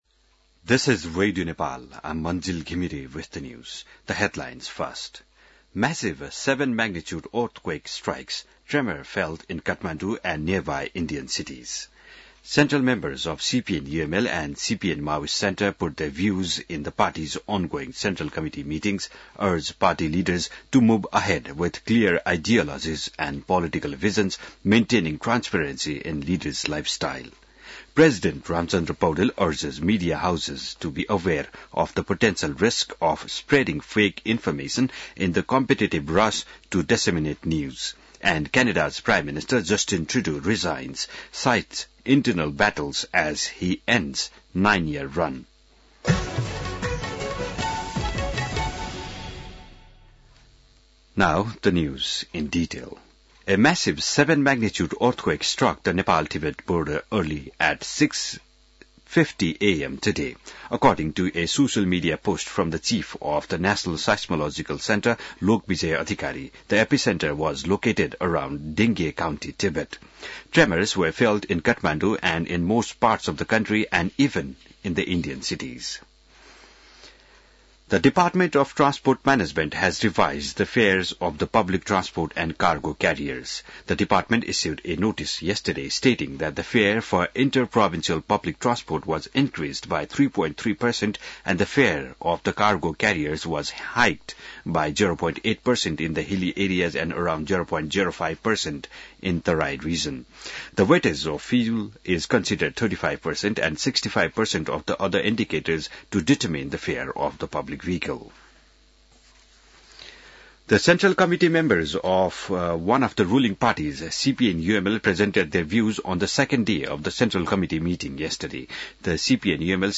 बिहान ८ बजेको अङ्ग्रेजी समाचार : २४ पुष , २०८१